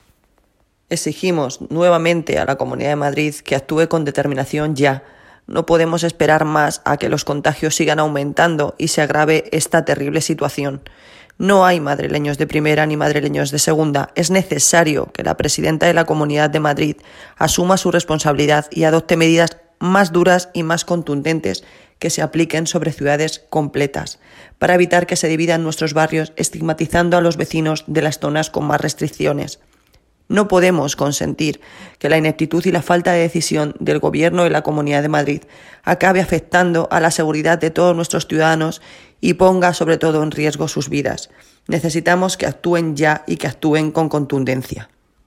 Audio - Noelia Posse (Alcaldesa de Móstoles)